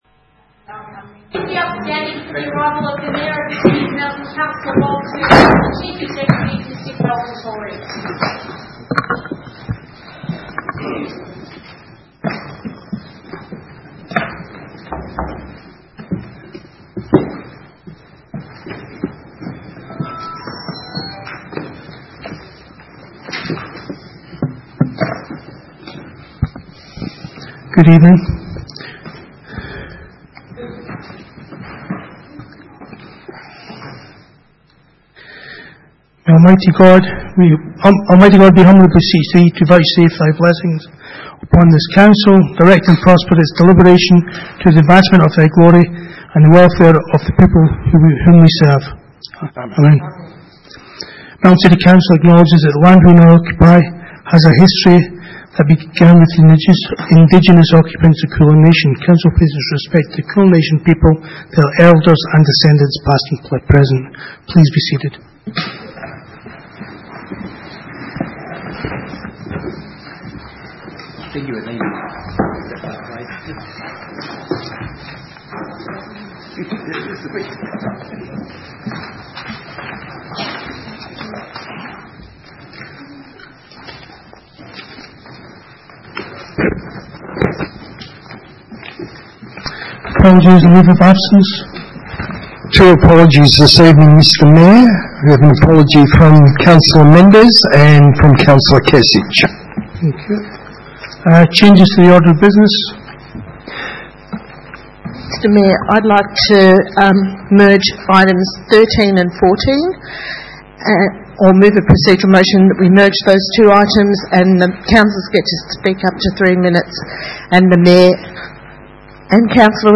Ordinary Meeting - 23 July 2018